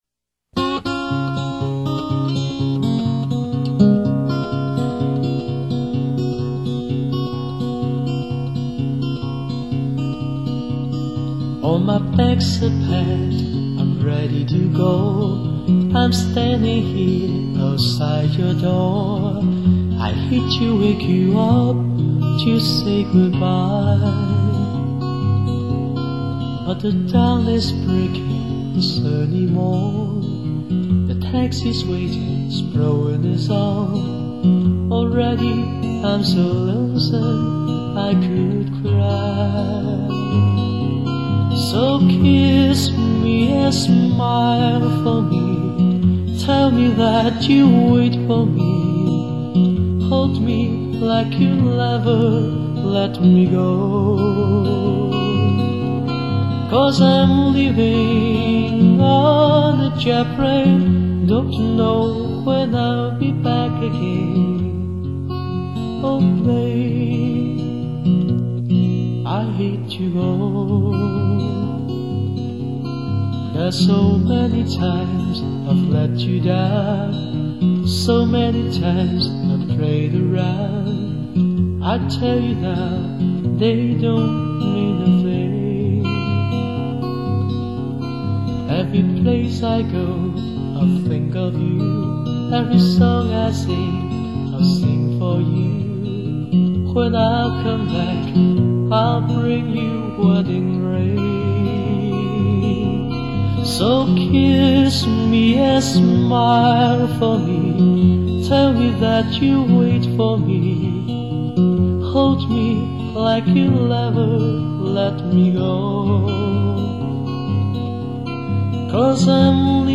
语    种：纯音乐
[广告语] 乡村宁静雅致，民谣清新质朴。